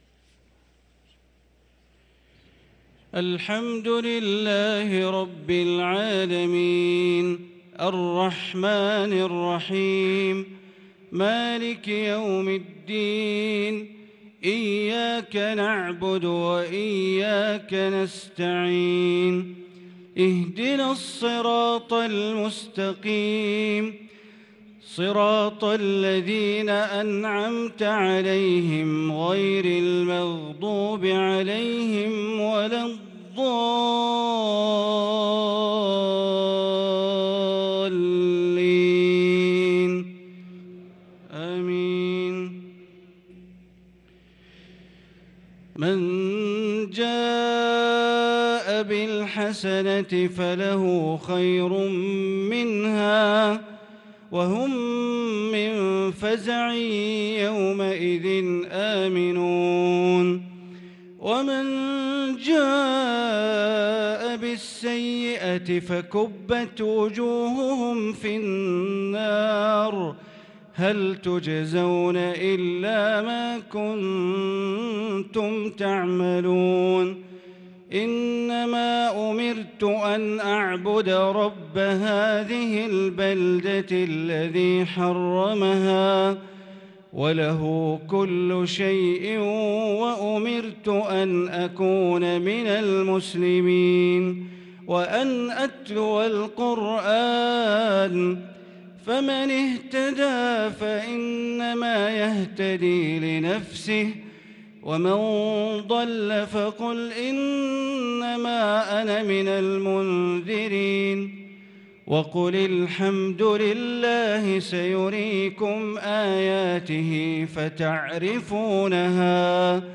صلاة العشاء للقارئ بندر بليلة 4 رمضان 1443 هـ